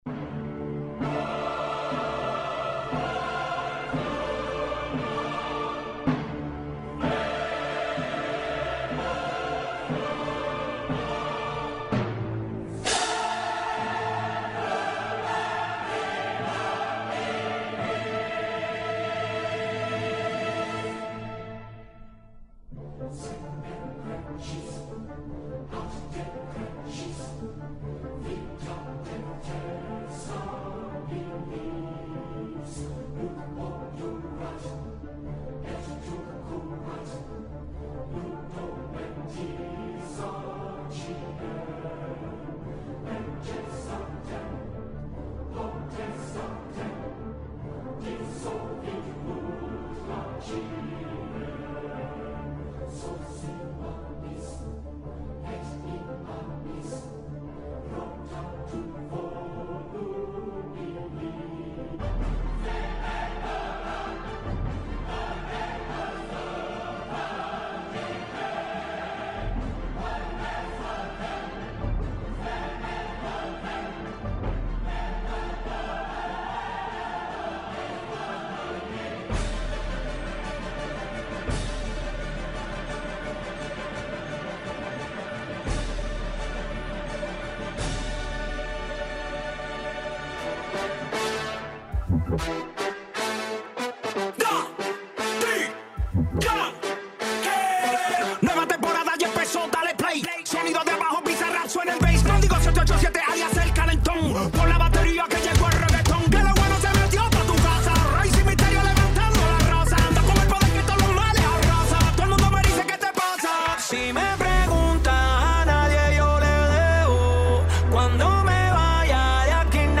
ruaensayo.mp3